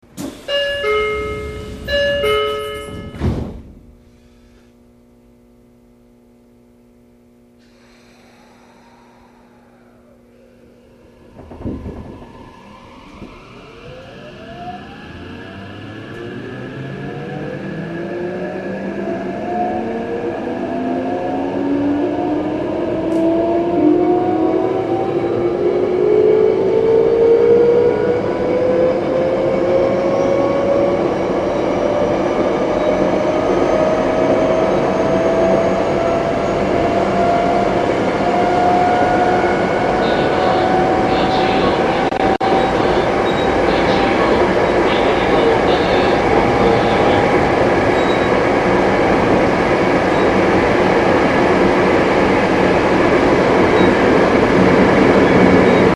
０５系11次車走行音 村上→八千代中央 881Kb MP3形式
11次車の走行音を一駅分そのまま収録しました。